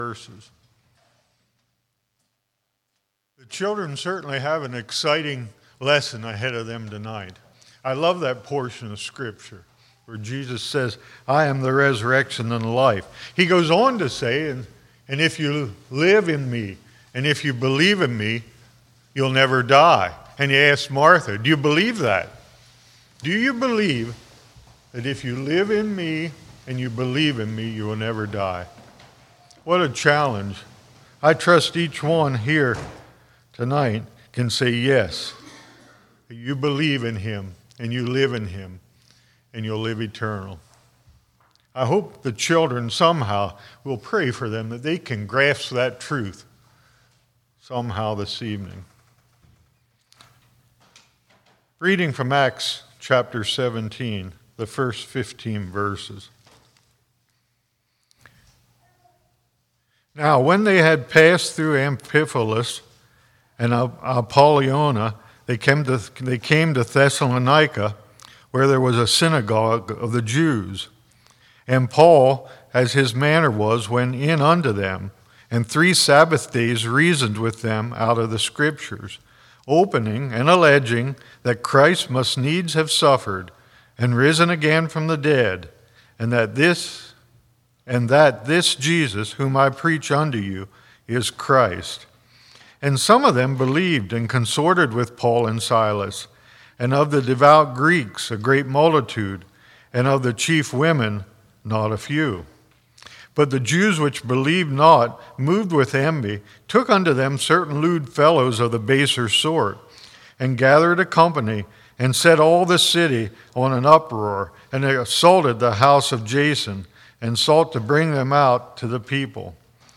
Winter Bible Study 2023 Passage: Acts 17:1-15 Service Type: Winter Bible Study Corinth Ephesus « Do We Agree with God?